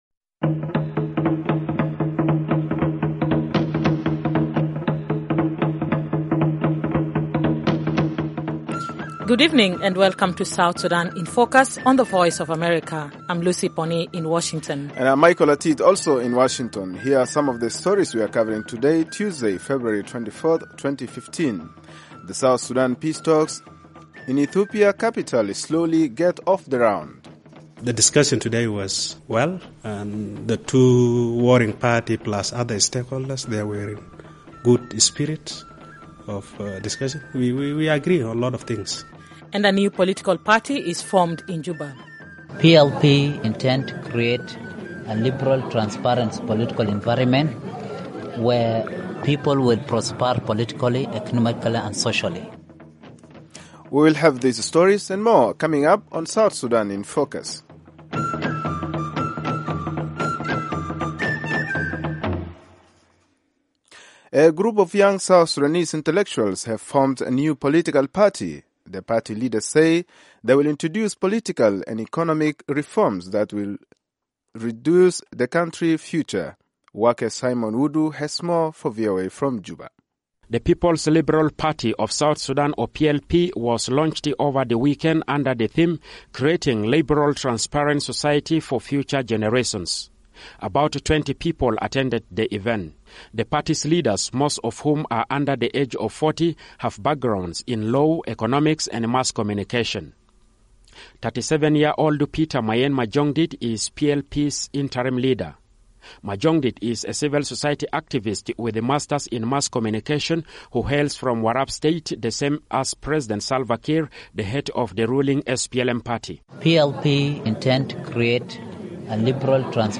South Sudan in Focus is a 30-minute weekday English-language broadcast/internet program covering rapidly changing developments in the new nation of South Sudan and the region.